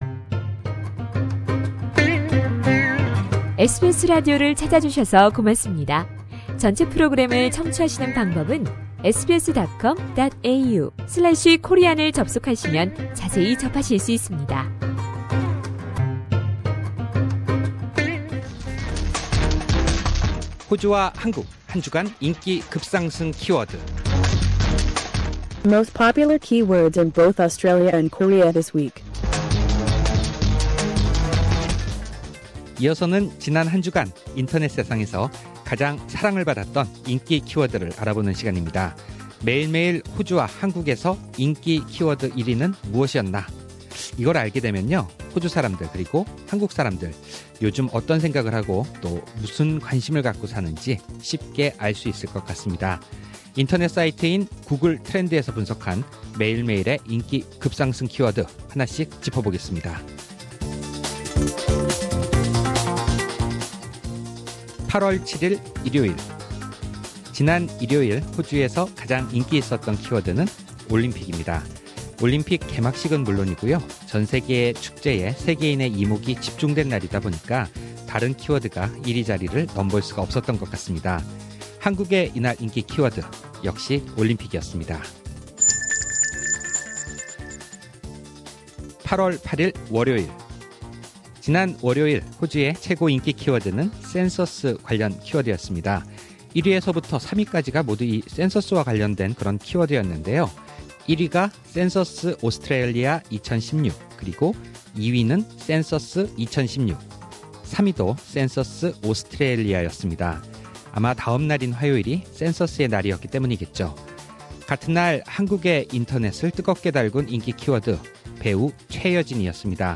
상단의 방송 다시 듣기 (Podcast)를 클릭하시면 라디오 방송을 다시 들으실 수 있습니다.